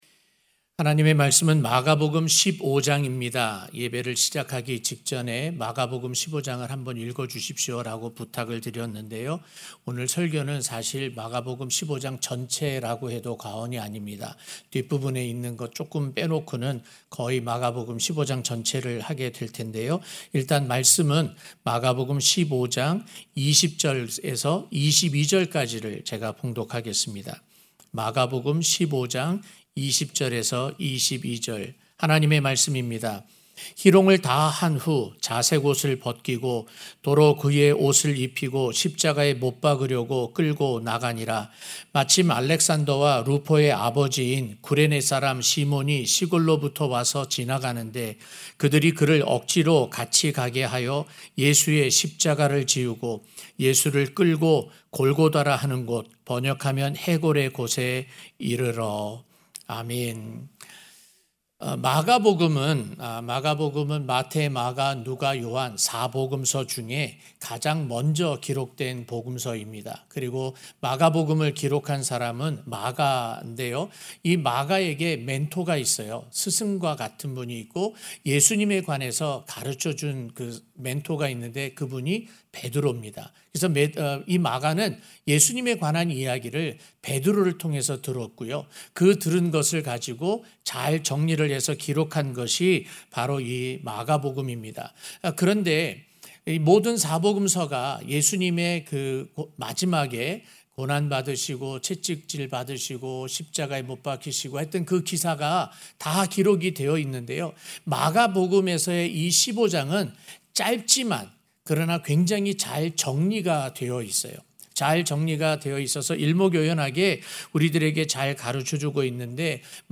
마지막 여정(막 15:20-22)-성금요일저녁예배 설교